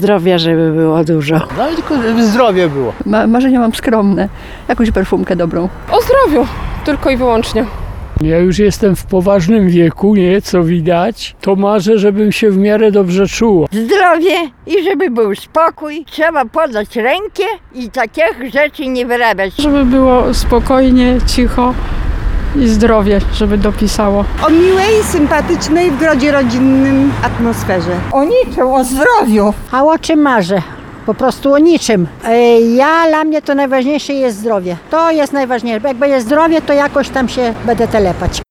– Zdrowia – najczęściej odpowiadają zapytani na ulicach Suwałk przechodnie. Święta Bożego Narodzenia to czas życzeń i marzeń.
O swoich życzeniach w te święta opowiadali przechodnie.